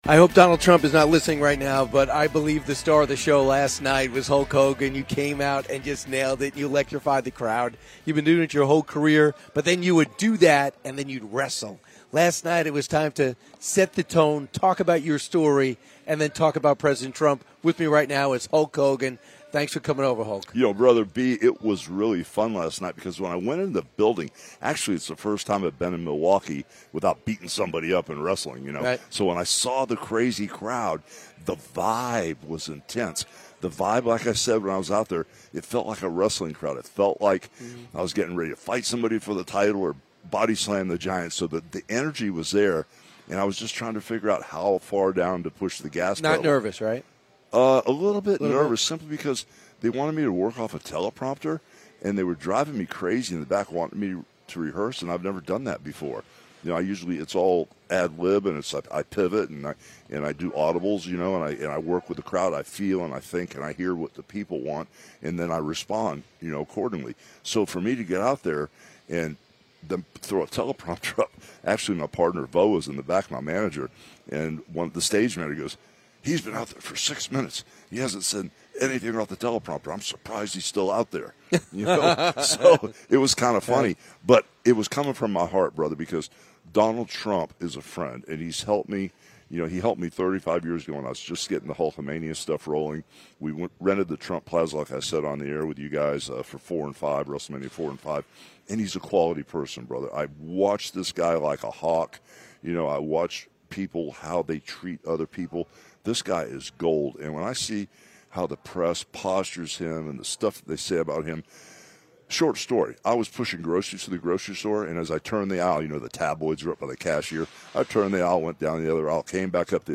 Hulk Hogan Donald Trump Wrestling Politics Leadership America Beer